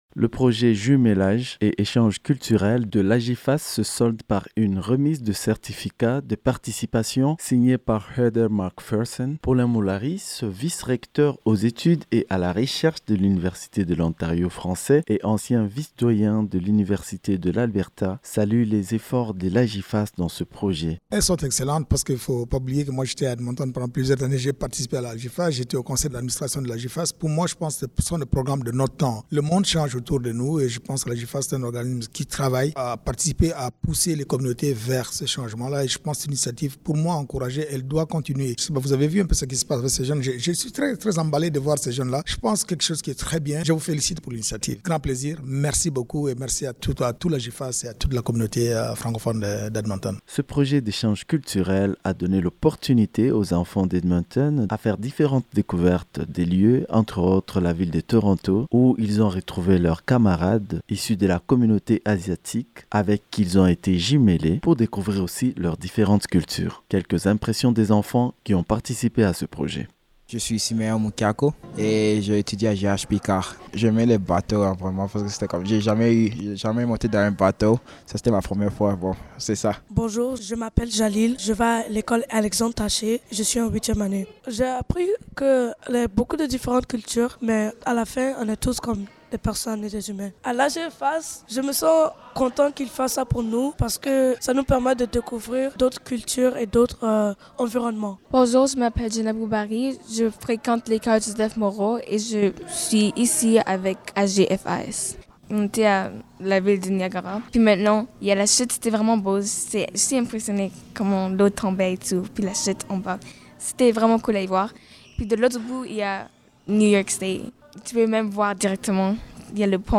Entrevue-_mixdown.mp3